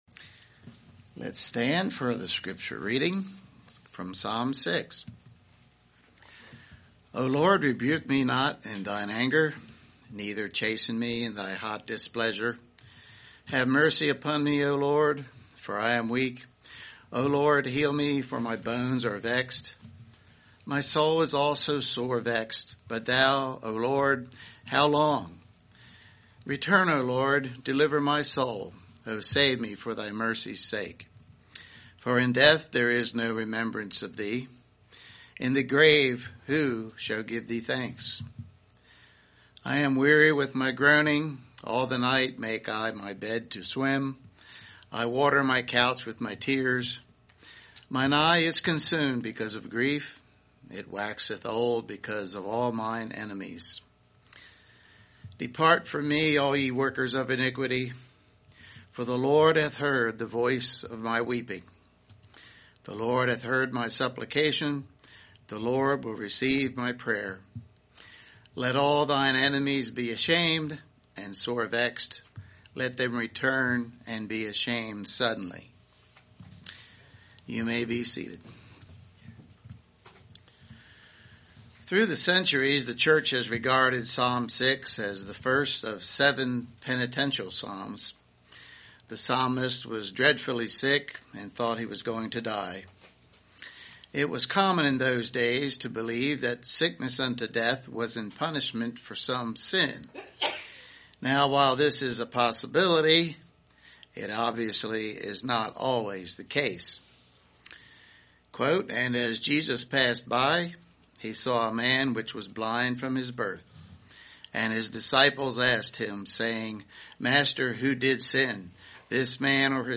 2021 Sermons